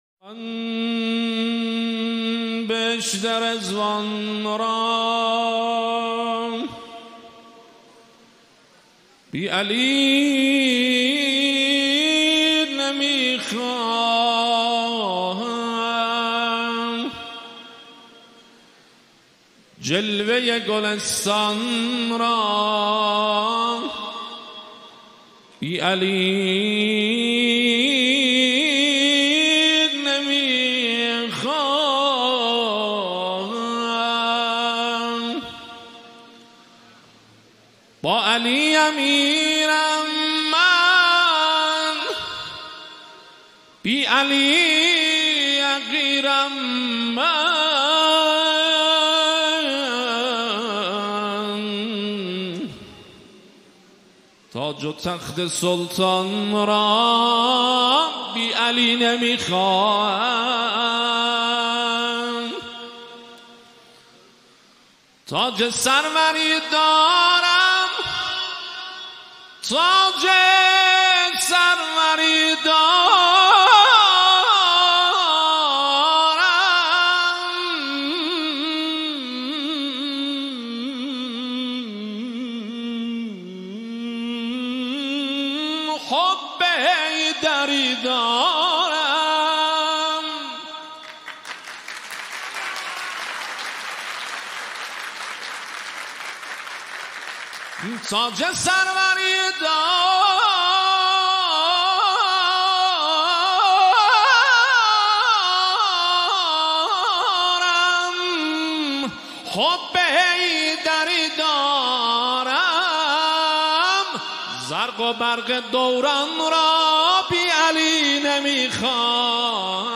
مولودی آذری مولودی ترکی ولادت حضرت امام علی علیه السلام